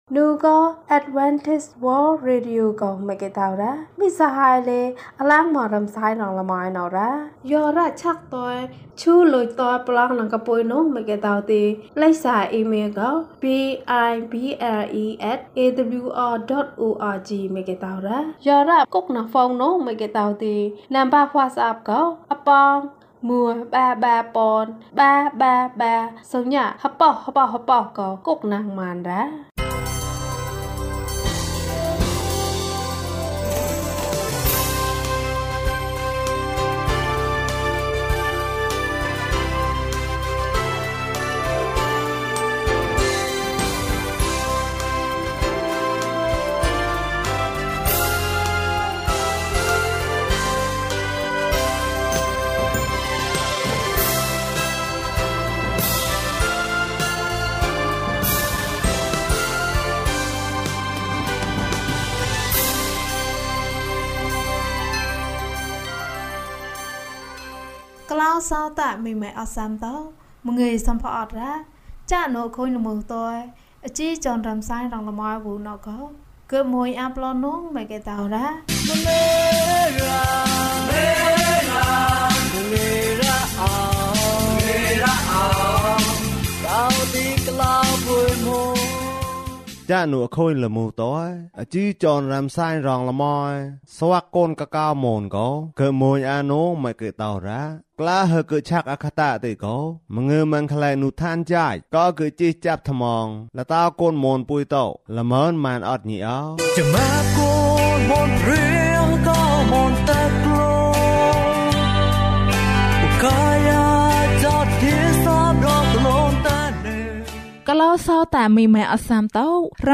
ဘုရားသခင်က လူတိုင်းကို ချစ်တယ်။ ကျန်းမာခြင်းအကြောင်းအရာ။ ဓမ္မသီချင်း။ တရားဒေသနာ။